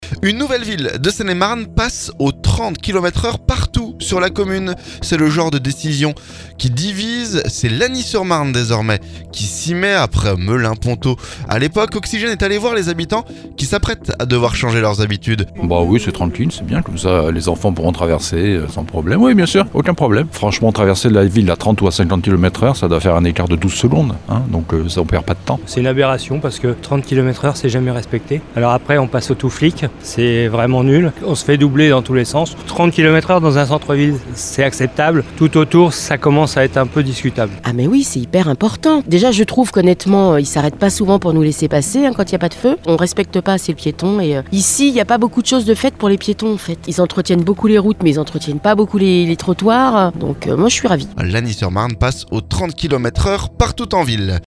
Oxygène est allé voir les habitants qui s'apprêtent à devoir changer leurs habitudes.